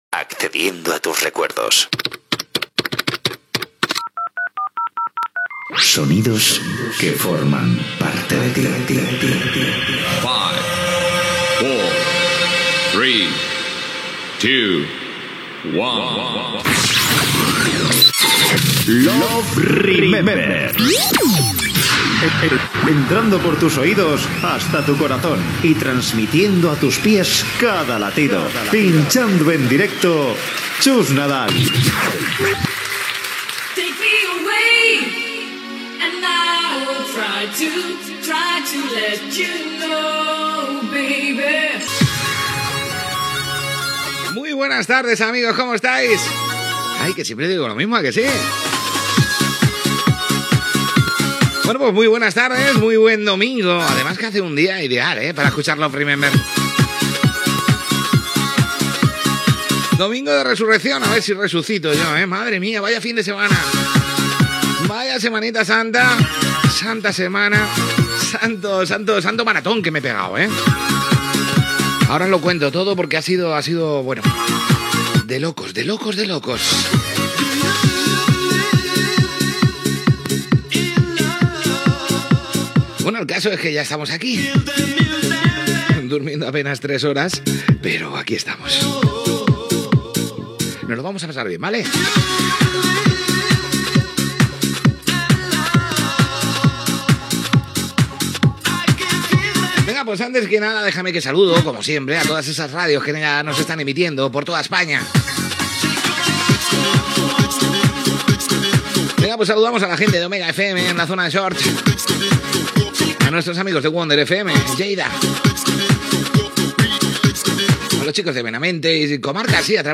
Careta del programa, salutació a les emissores que emeten el programa amb esment a Wonder FM
Musical